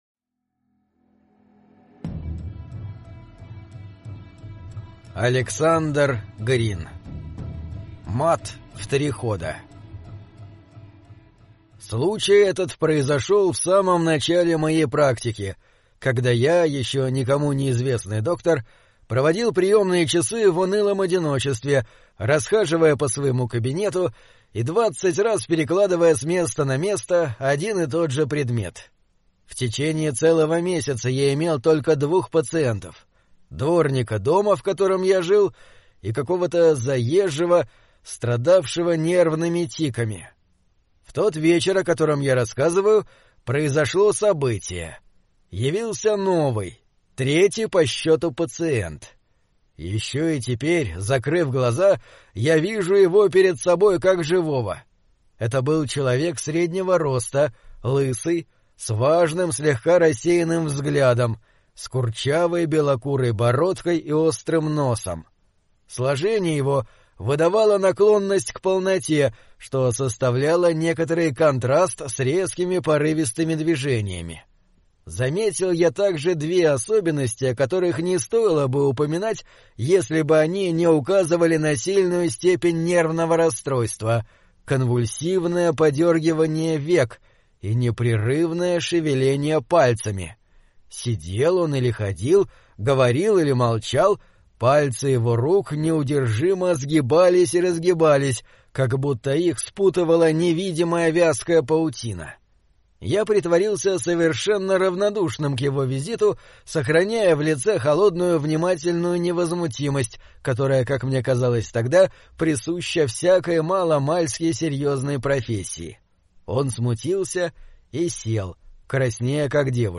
Мат в три хода — слушать аудиосказку Александр Грин бесплатно онлайн